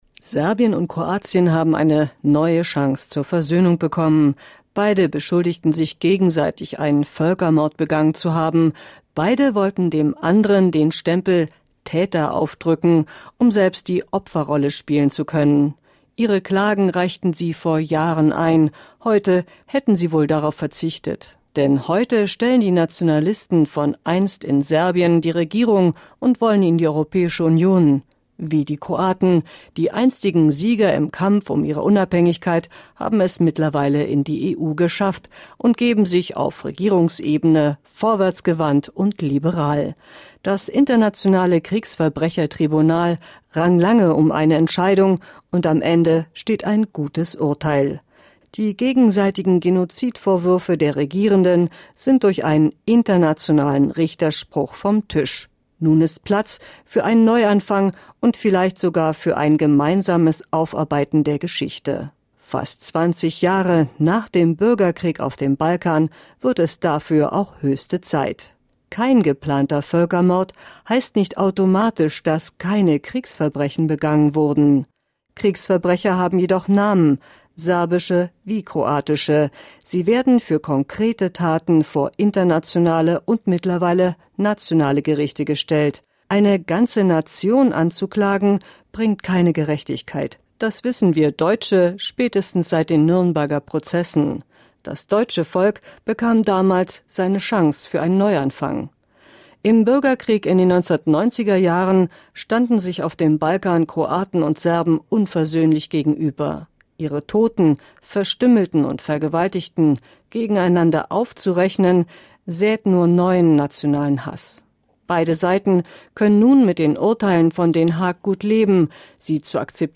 Kommentar